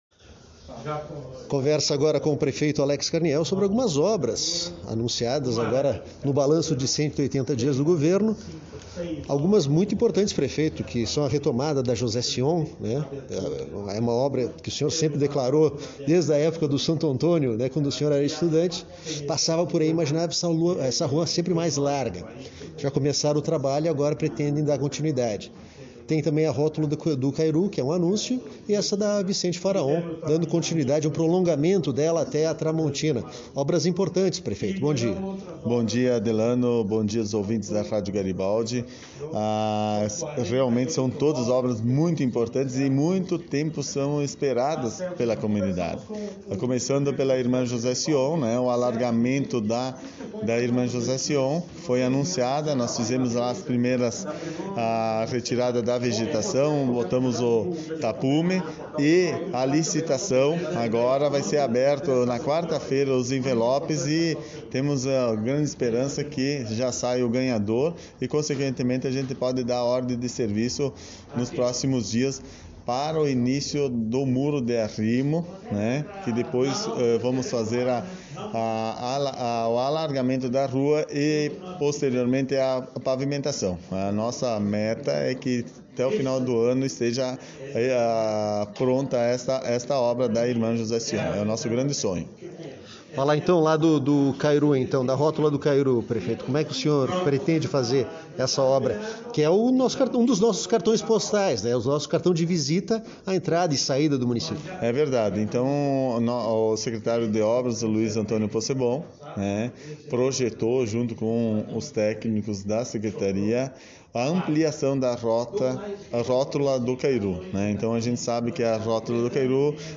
O prefeito Alex fala sobre as obras.
(entrevista em ouça a notícia)